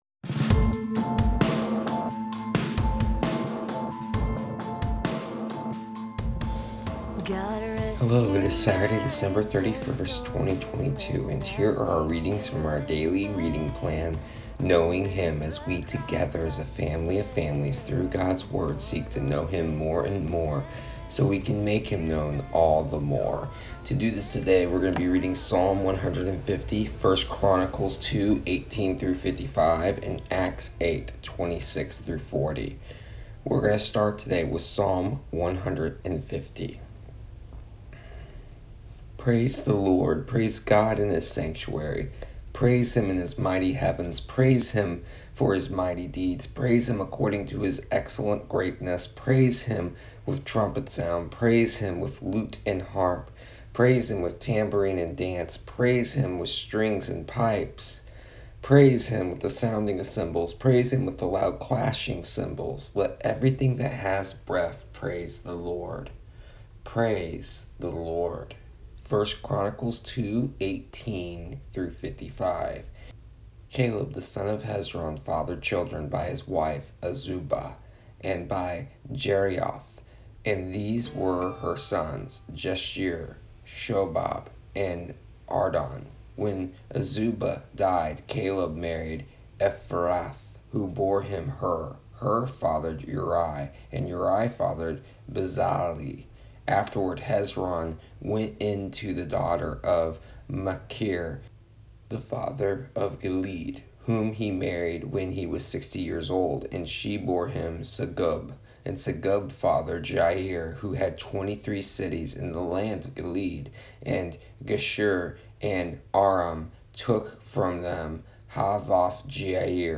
Here is the audio version of our daily readings from our daily reading plan Knowing Him for December 31st, 2022.